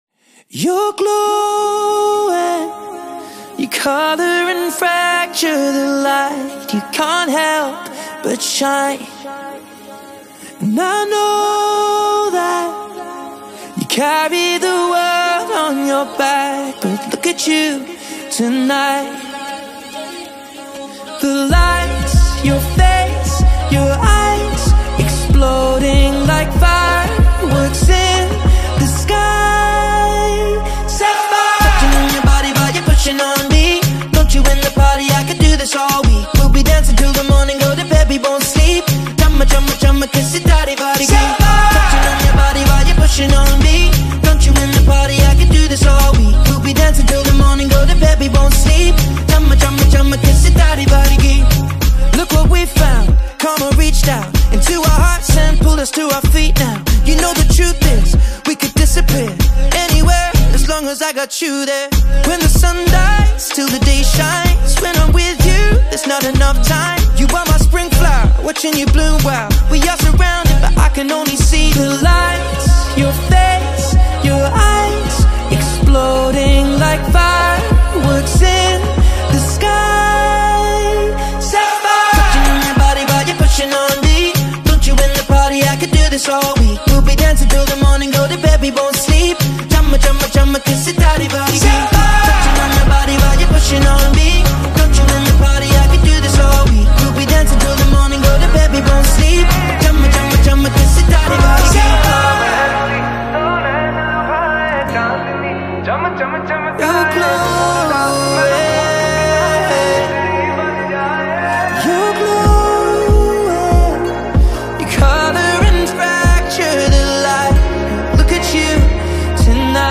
English Songs